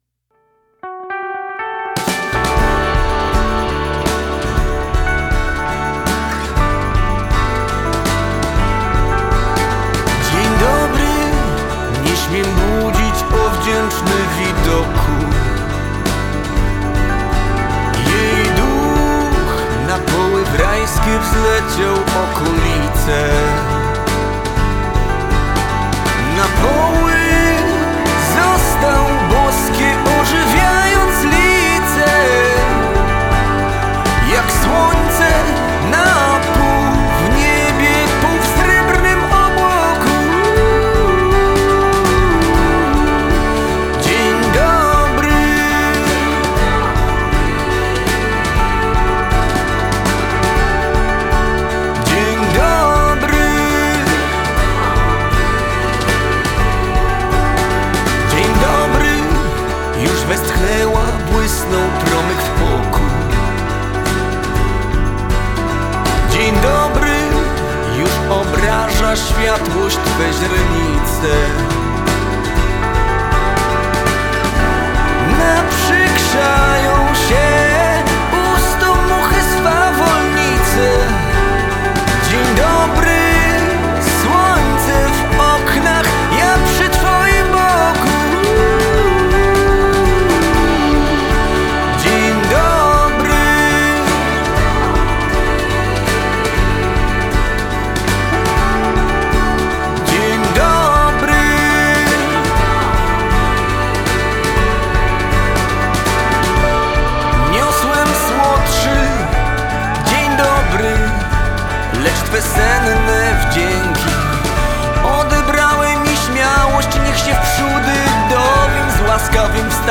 Singiel (Radio)
muzycznej aranżacji wiersza